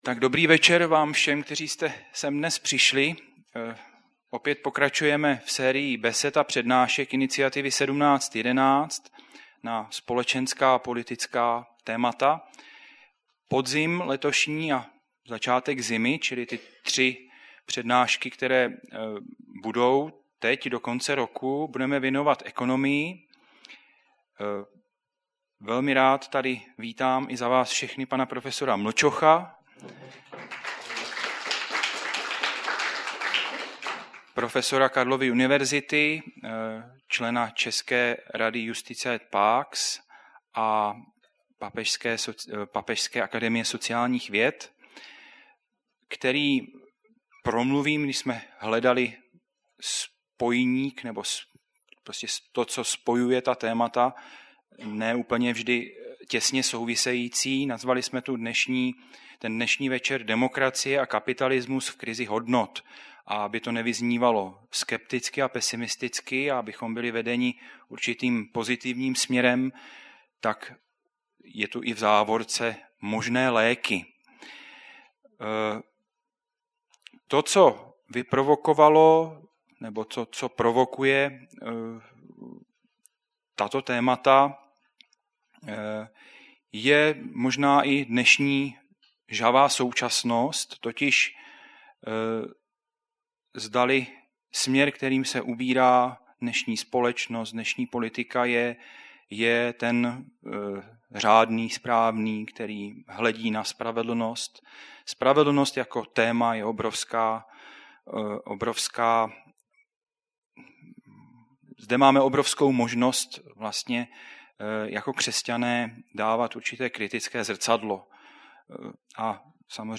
Iniciativa 17-11 - cyklus přednášek o sociální nauce církve.
Zvukový záznam přednášky